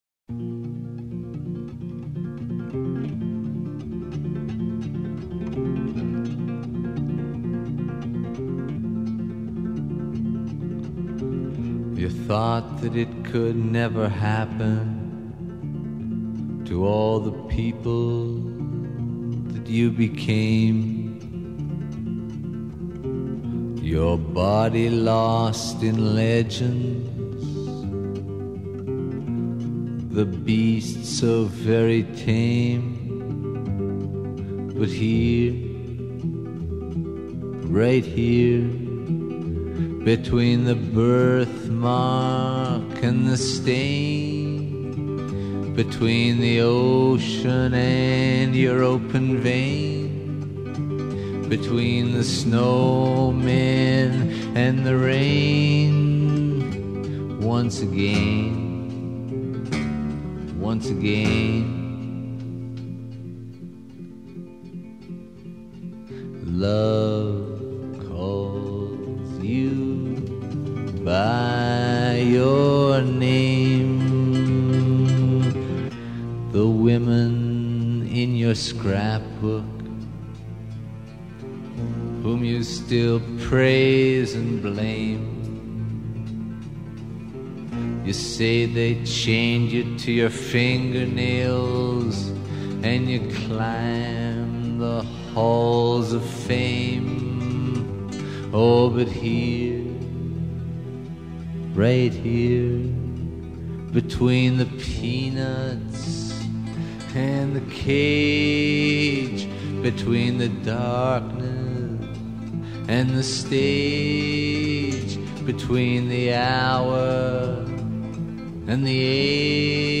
Фолк музыка